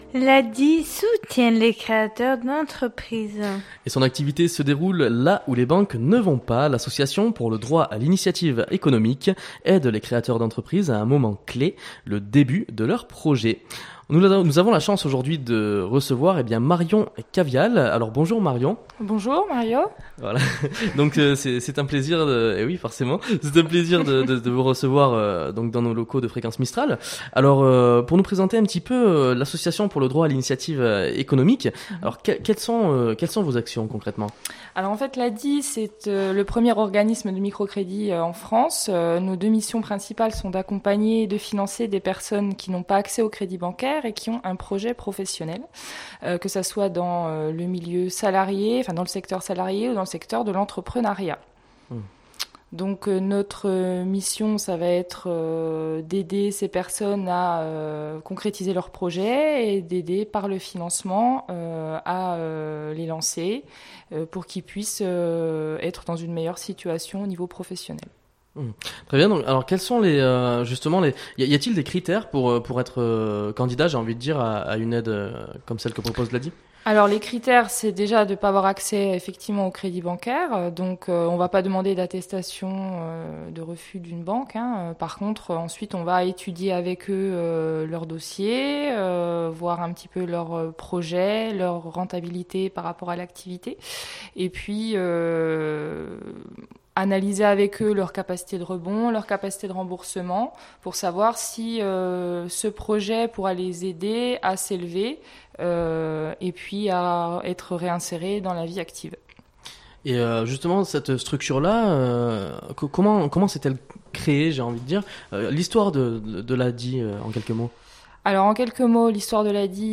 L’Association pour le Droit à l’Initiative Economique aide les créateurs d’entreprises à un moment clé : le début de leur projet. Interviewée